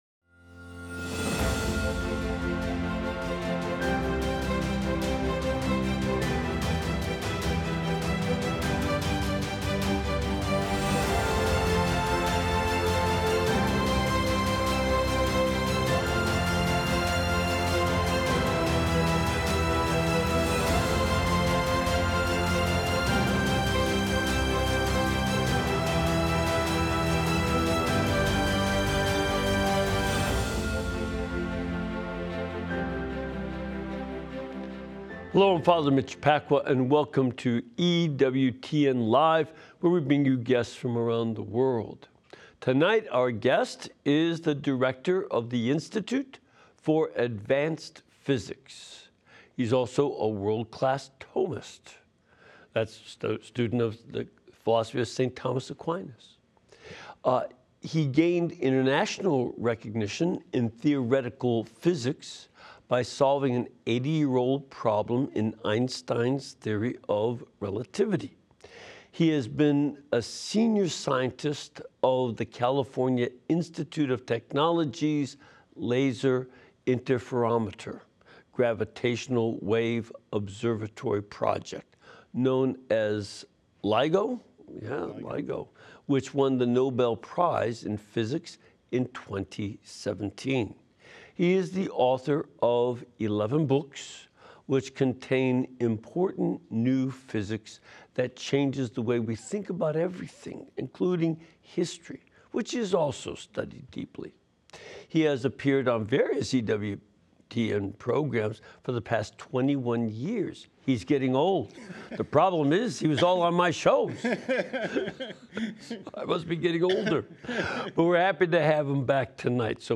interviews various guests seeking to teach and prepare us for evangelism. Learn more about your faith and reawaken your desire to bring others to the Church.